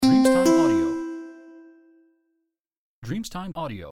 Carillon 0002 di multimedia di notifica del messaggio
• SFX